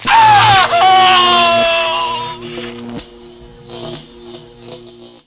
electrocute.wav